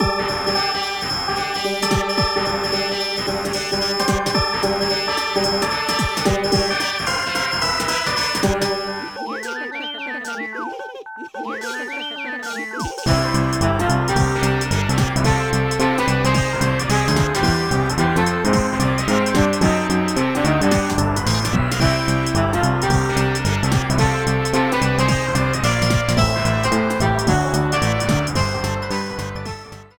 Clipped to 30 seconds and applied fade-out with Audacity